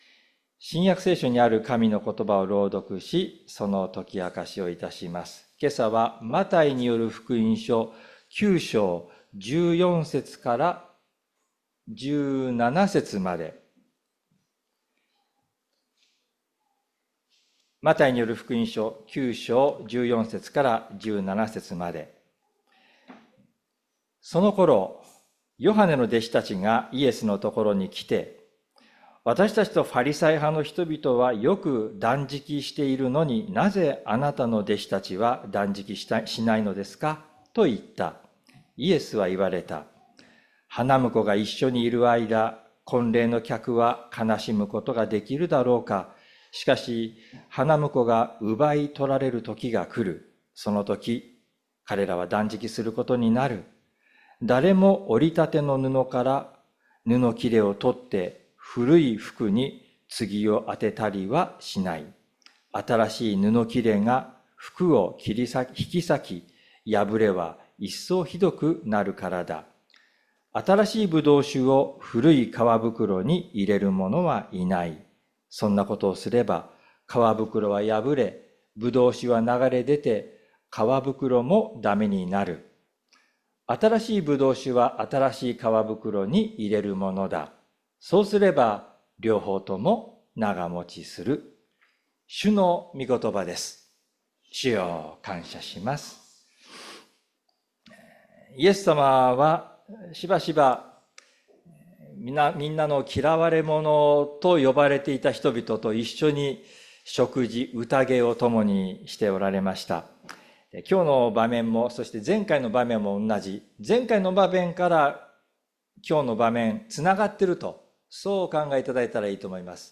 礼拝説教を録音した音声ファイルを公開しています。